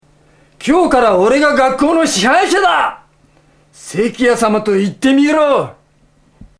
ついに楳図かずお自らが、己の作品のワンシーンに命を吹き込んむ新企画「UMEZZ VOICE!」が始まりました！